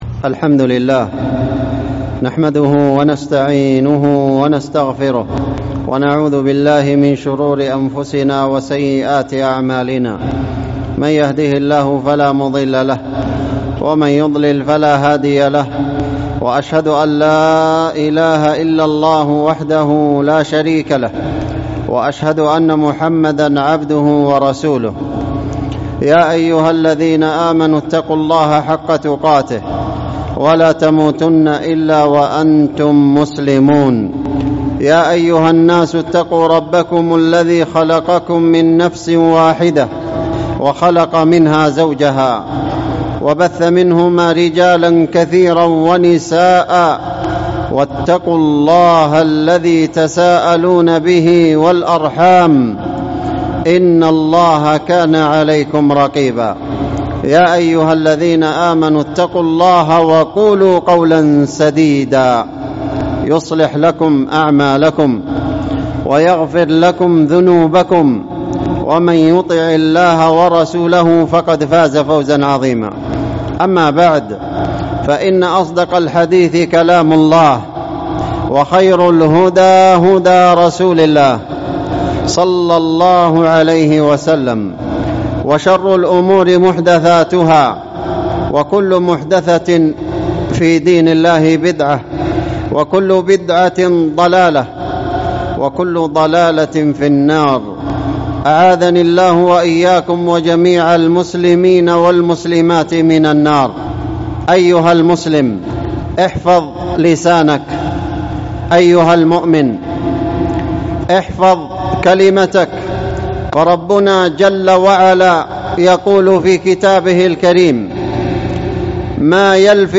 احفظ كلمتك | الخطب والمحاضرات